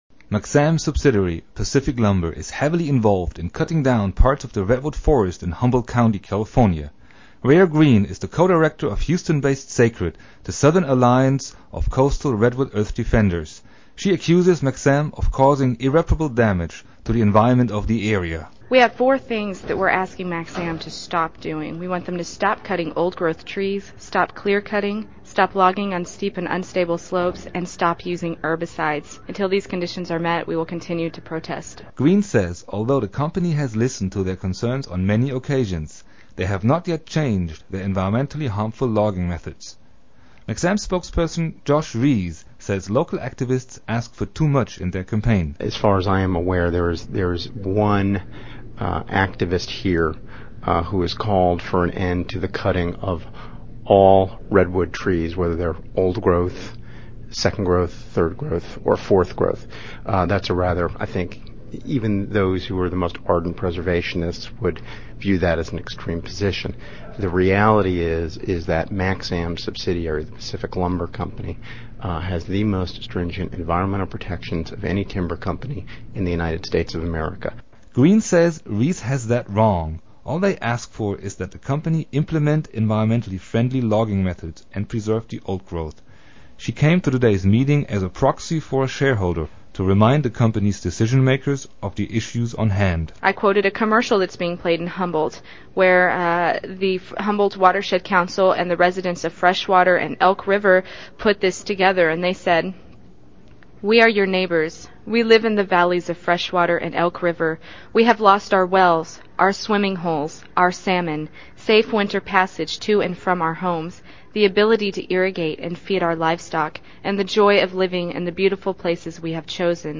KPFT News Reports on MAXXAM Shareholder Meeting